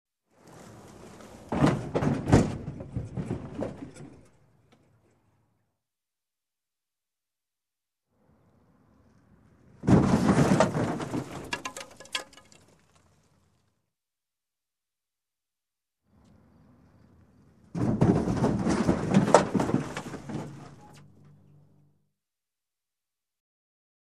Здесь собраны реалистичные аудиоэффекты: скрип половиц, завывание ветра в пустых комнатах, отдаленные голоса и другие жуткие детали.
Звук призрачных шорохов в старинном особняке